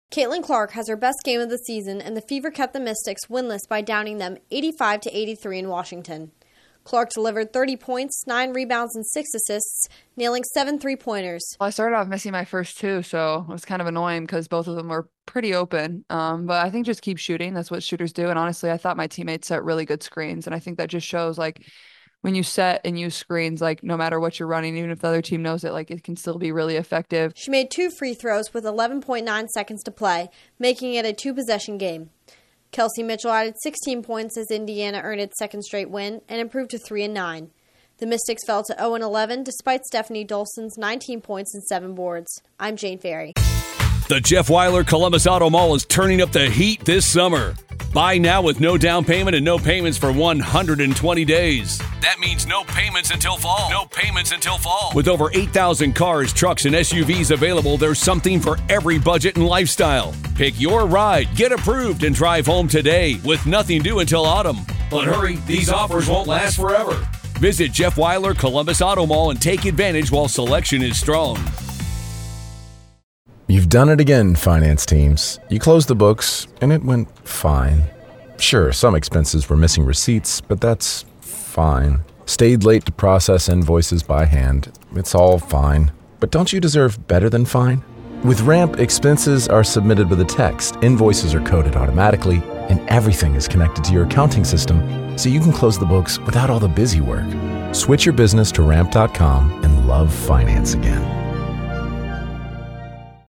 The WNBA's first overall pick has her finest day as a pro. Correspondent